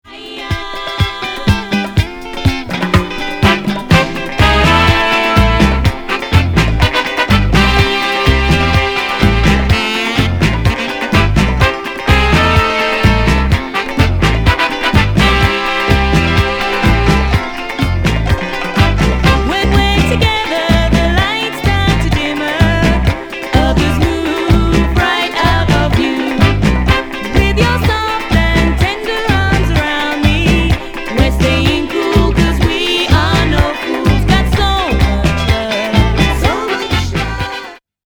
UKレゲー、カリプソ、ソカ、ゴッチャマゼのファンカラティーナ傑作！！
2トーン・カリプソな
全体的に程よくポップなところがなんともナイス！！